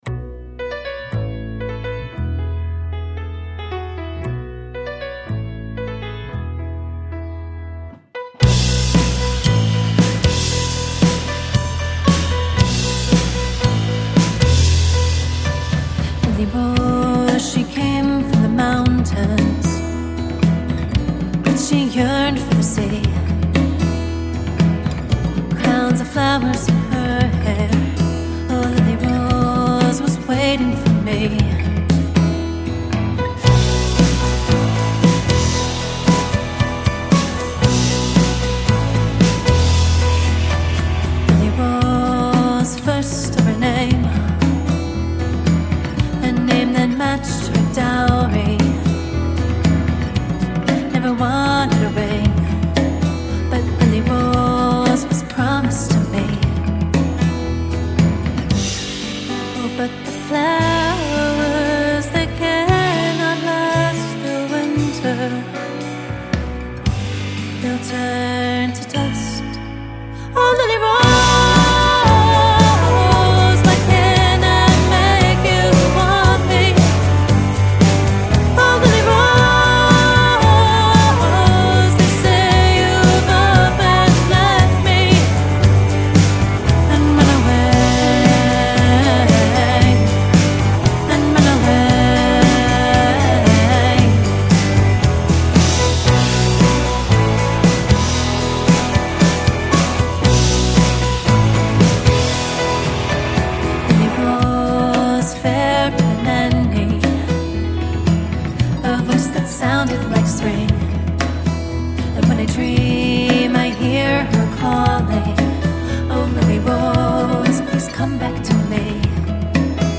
Celtic soul couples with piano-driven folk